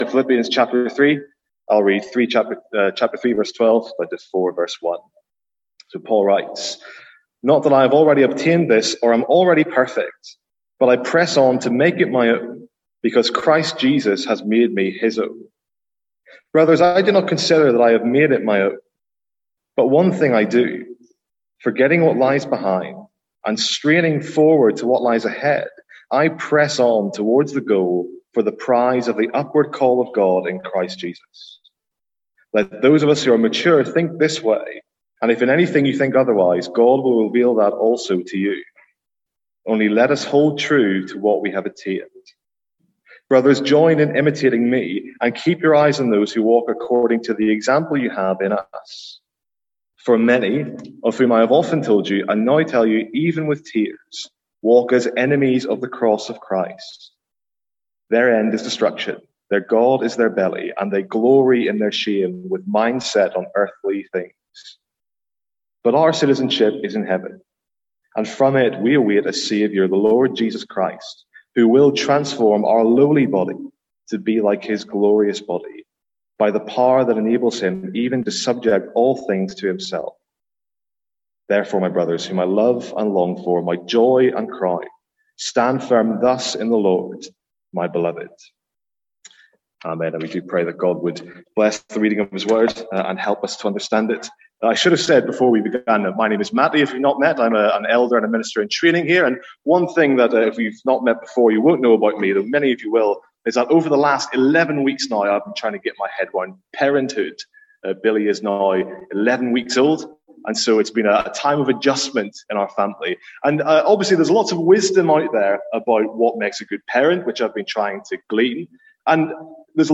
Sermons | St Andrews Free Church
From our morning series in Philippians